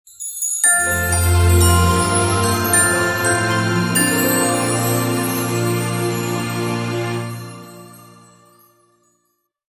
Мелодия для начала или вставки в сюжет о начале Рождества